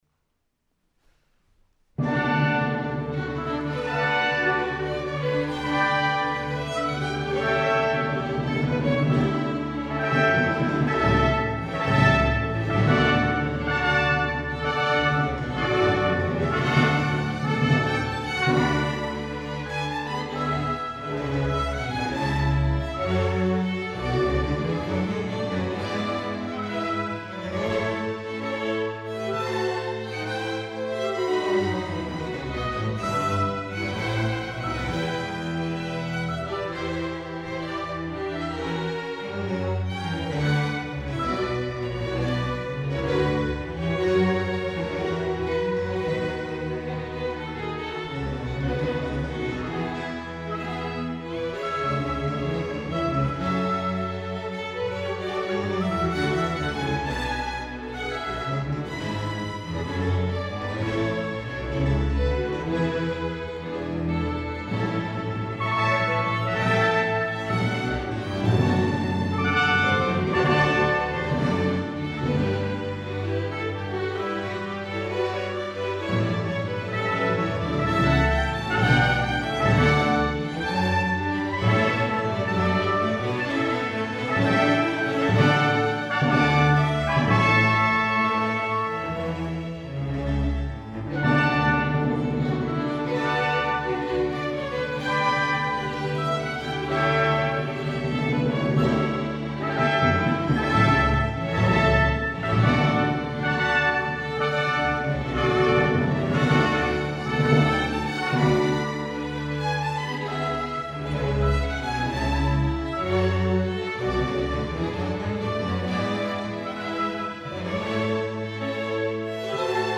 Concert recordings
Silicon Valley Symphony Choice Chamber Music Concert
(Saturday, January 29th at St. Mark’s Episcopal Church)